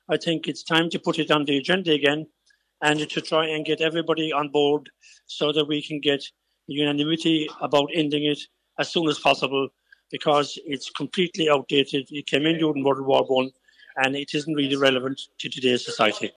Ireland South MEP Sean Kelly wants pressure put on the European Commission to scrap the clocks changing twice a year: